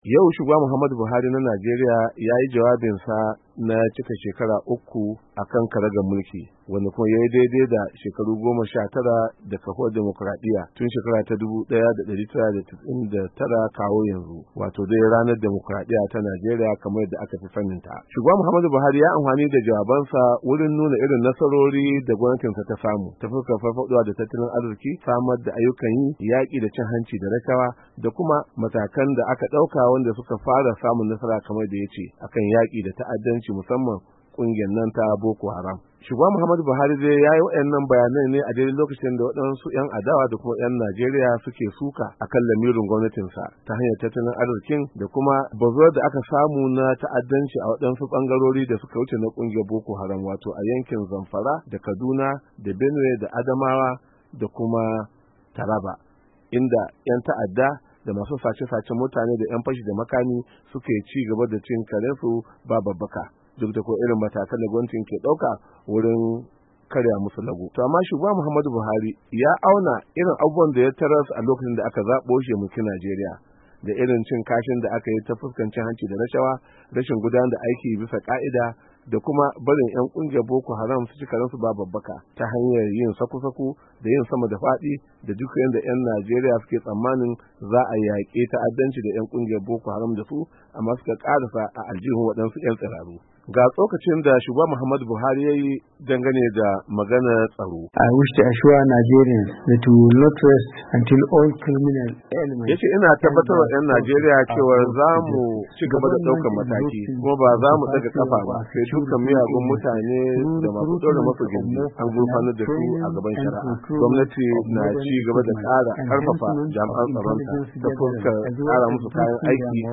WASHINGTON DC —